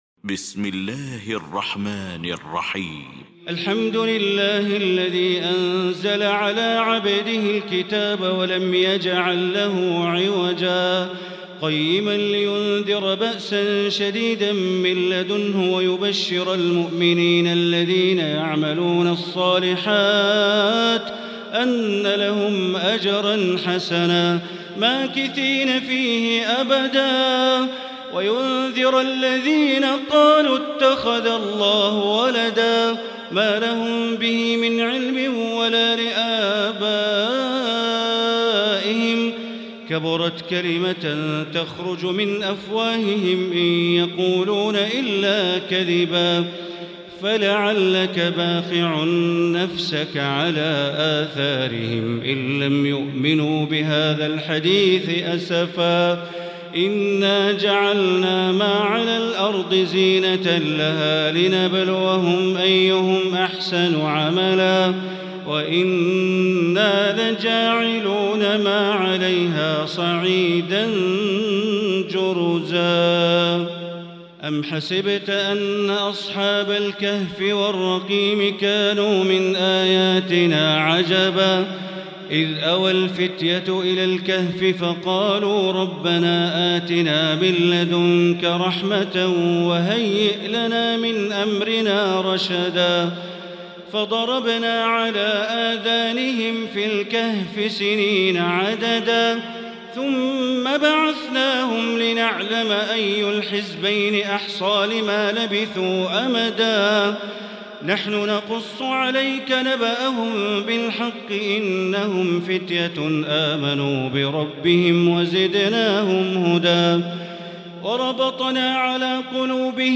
المكان: المسجد الحرام الشيخ